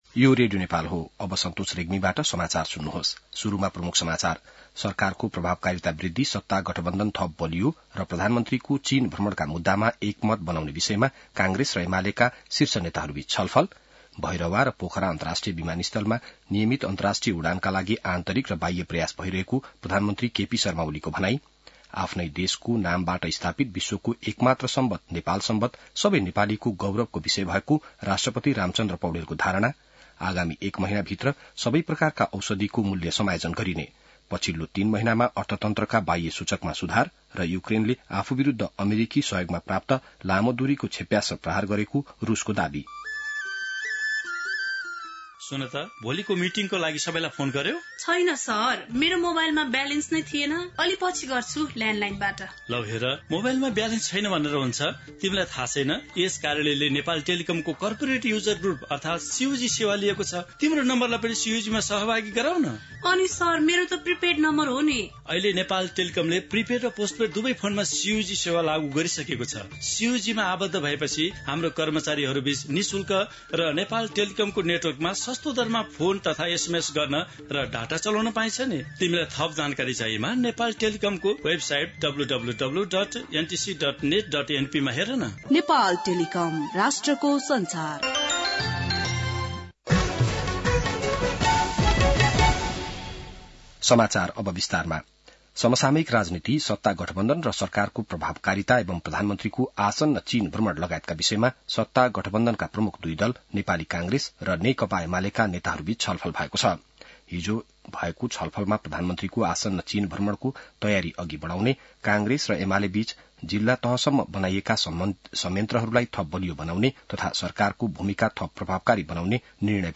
बिहान ७ बजेको नेपाली समाचार : ६ मंसिर , २०८१